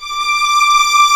55m-orc14-D5.wav